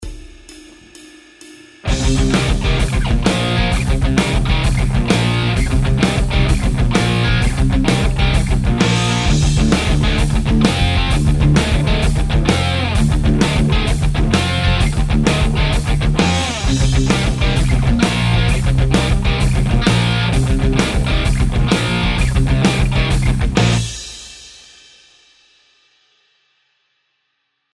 Egy másik téma akkordmenete a következő: Cm Bb, Do F (